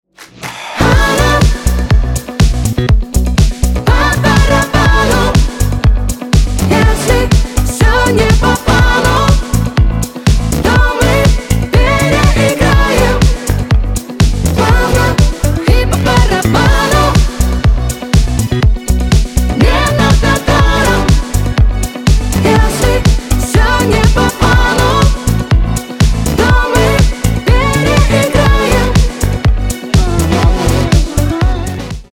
• Качество: 320, Stereo
позитивные
женский голос